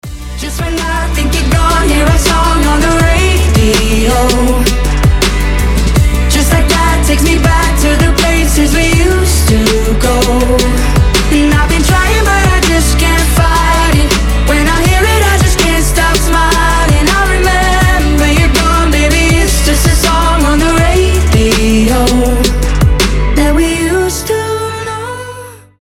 • Качество: 320, Stereo
грустные
дуэт